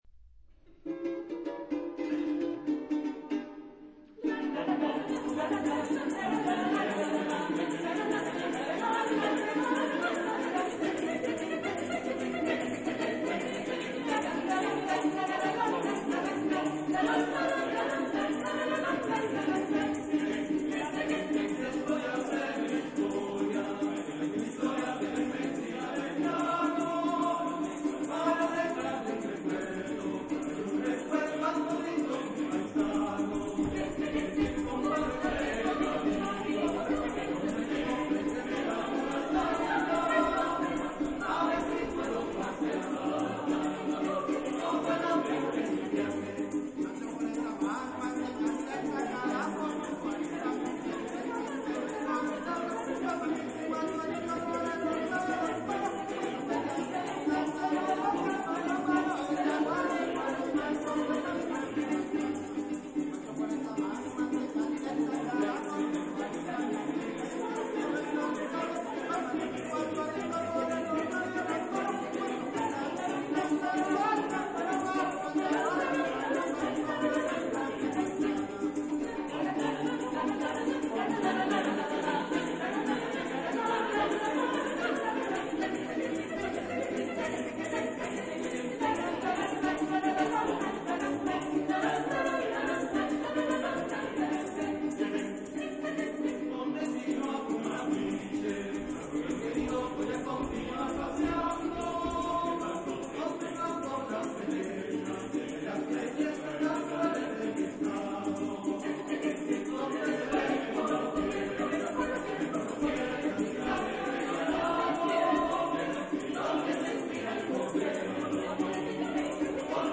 Genre-Stil-Form: Volkstümlich ; Pasaje ; Lateinamerikanisch ; weltlich
Charakter des Stückes: schnell
Chorgattung: SATB  (4 gemischter Chor Stimmen )
Solisten: Tenor (1)  (1 Solist(en))
Instrumentation: Cuatro
Tonart(en): G-Dur
Aufnahme Bestellnummer: Internationaler Kammerchor Wettbewerb Marktoberdorf